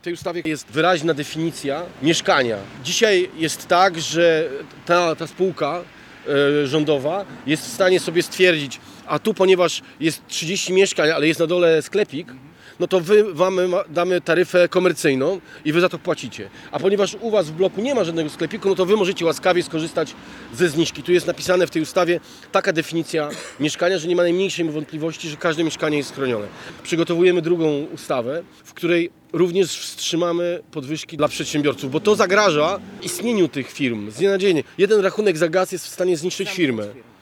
O szczegółach ustawy oraz o nowej, tym razem chroniącej przedsiębiorców mówił poseł Sławomir Nitras.
Konferencja odbyła się w pobliżu szczecińskiego Biura Obsługi Klienta PGNiG, gdzie przed jego wejściem, interesanci czekali w długiej kolejce na załatwienie swoich spraw.